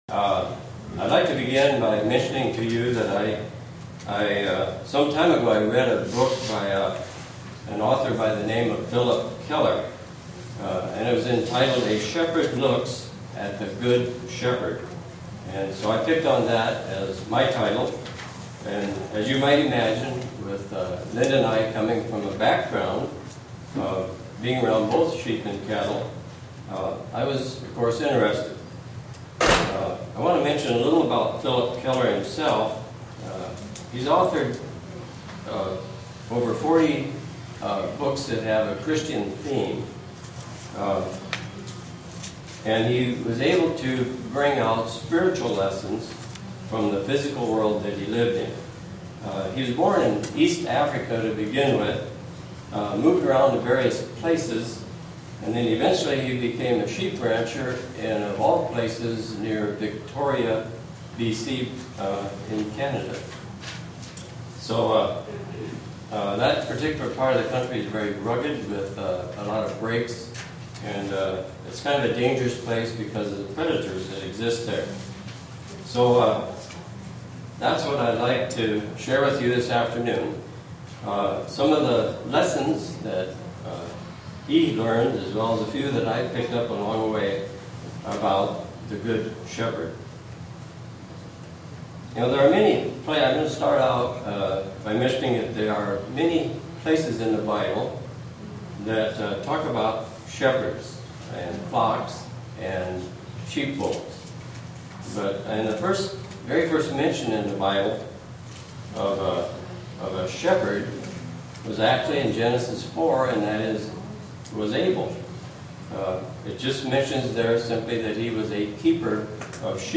Print The seventh day of the Feast of Tabernacles in Estonia SEE VIDEO BELOW UCG Sermon Studying the bible?